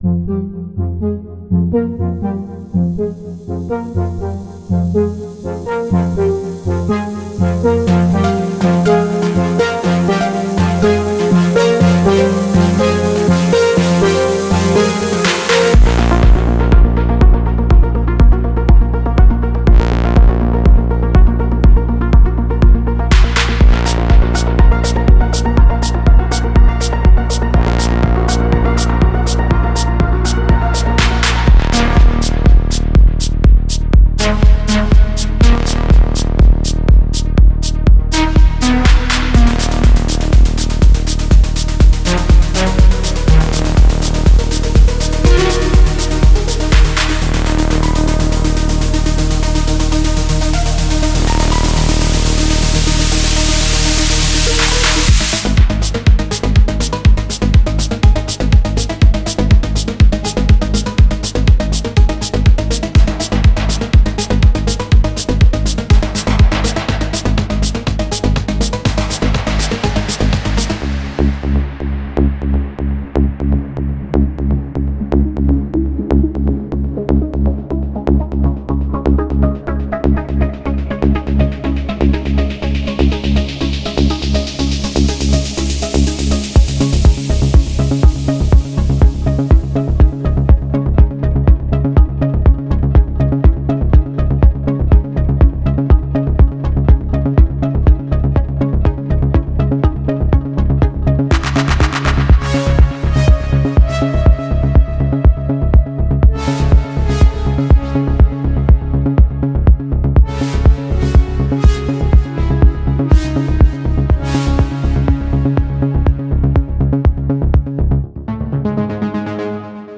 Deep House
Downtempo
Progressive House